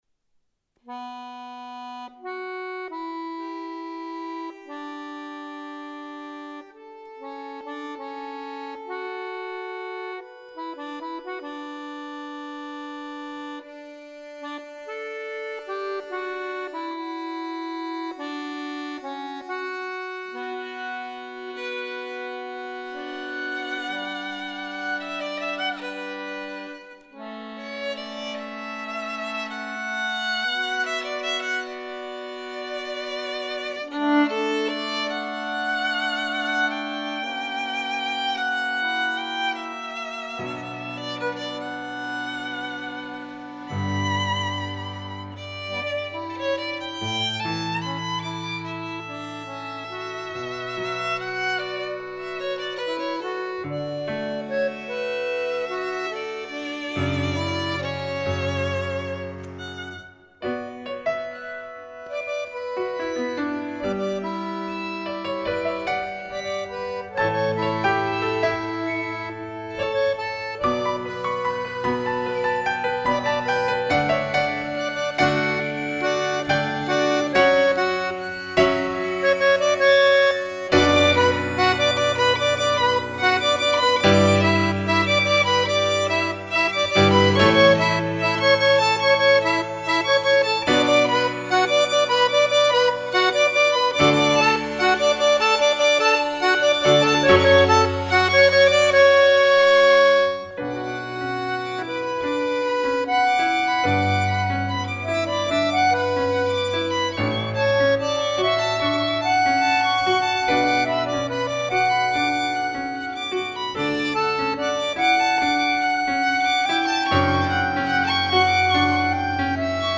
Fisarmonica, Violino e Pianoforte